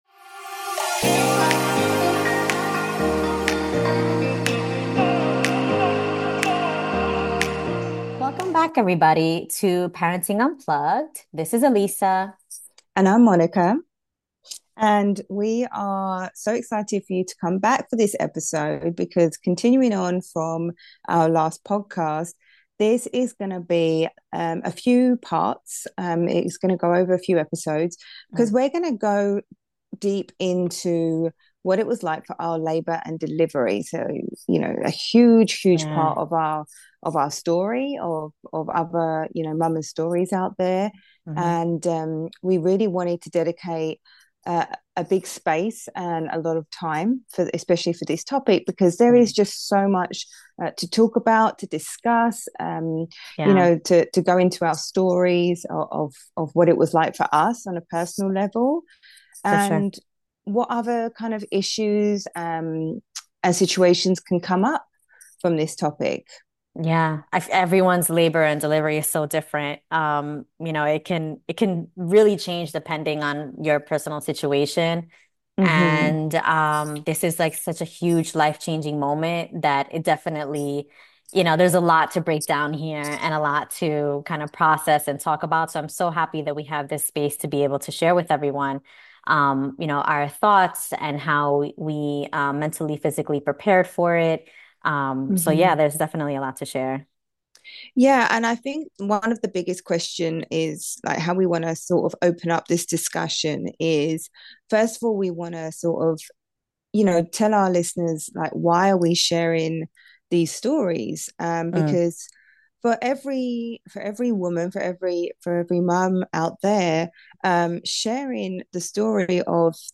Join us for an honest and intimate conversation about what it means to prepare for labor, embrace the unknown, and share in the collective experience of bringing new life into the world.